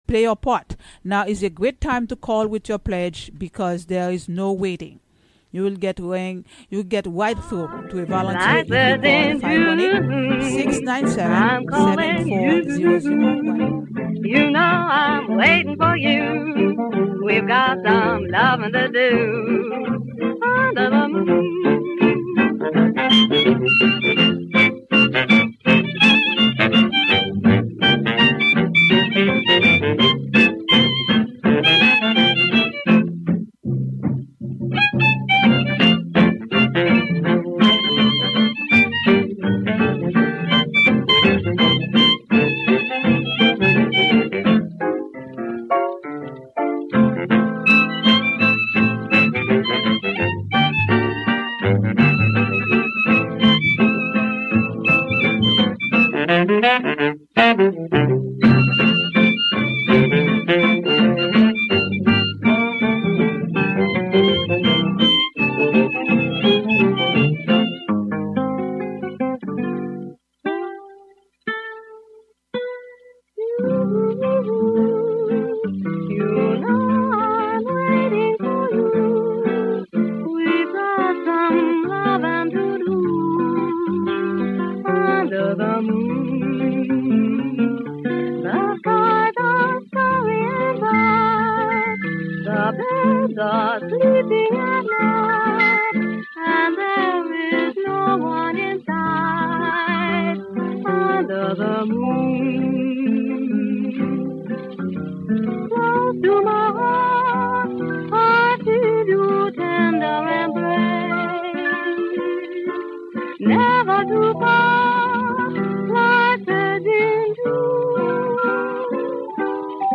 plays a half hour of 1930s and 1940s music, with an occasional foray into other genres. Broadcast live from the Hudson studio.